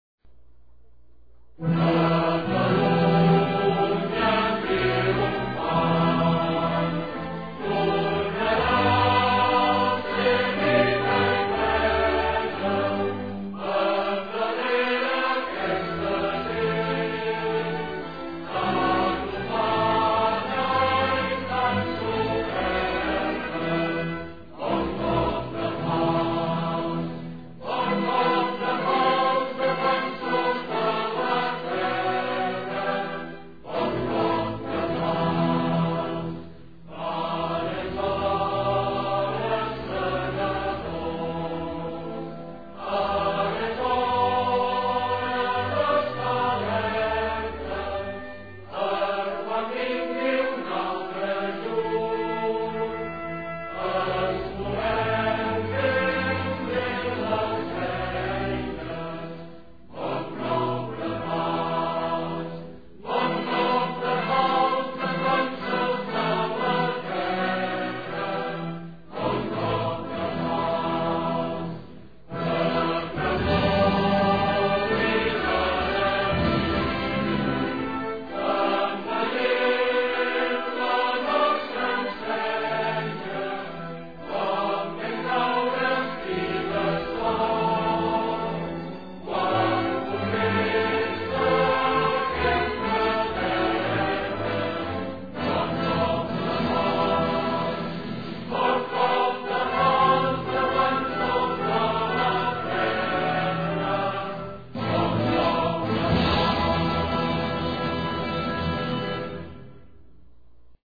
cantado